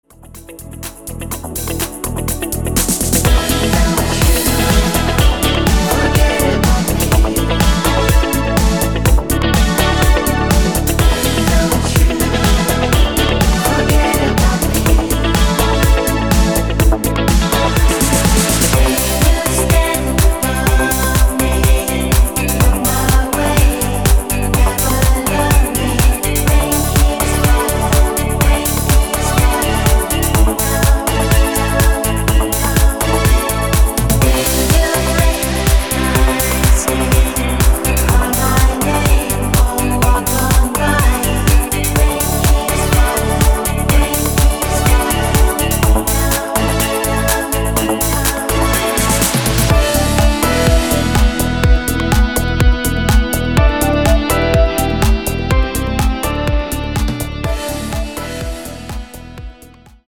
Rhythmus  Easy Disco